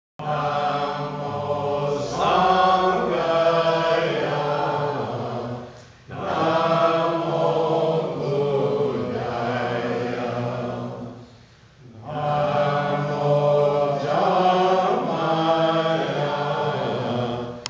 Travels, Blue Bunny Retreat, 2010
At the last teaching, he introduced us to a chant in sanskrit taking refuge in the three jewels, the Buddha, Dharma, and Sangha.
The tune repeats after two lines so you have to do six lines for a complete set.